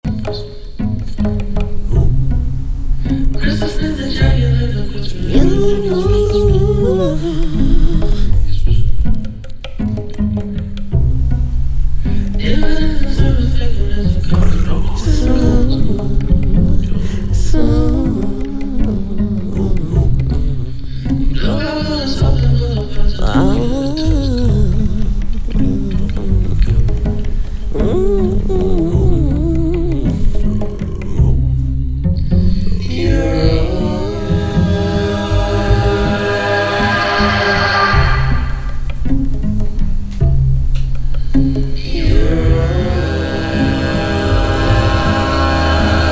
experimental RnB
Electronix Hip Hop